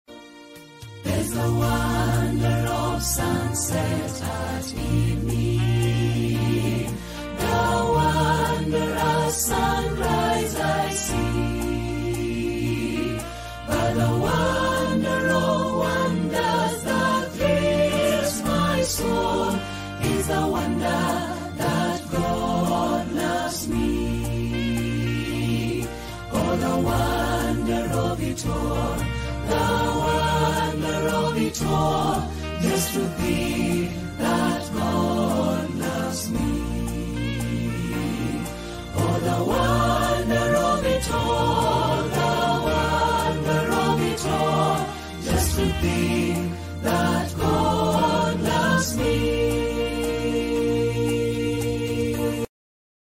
Hymns for Worship